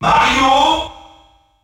The announcer saying Mario's name in French releases of Super Smash Bros.
Mario_French_Announcer_SSB.wav